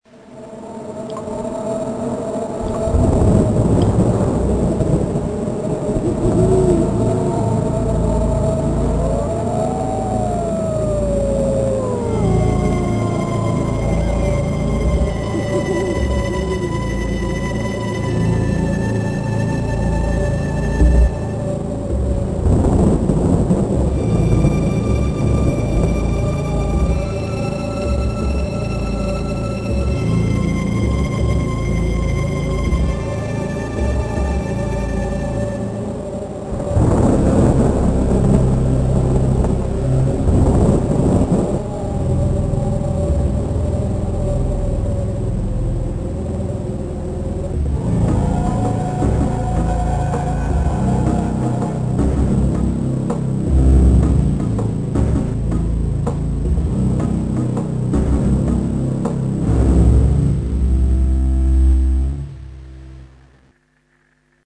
frightening, and eerie.
or deep celli and contrabasses.
After that I converted the samples to 8 bit Mono.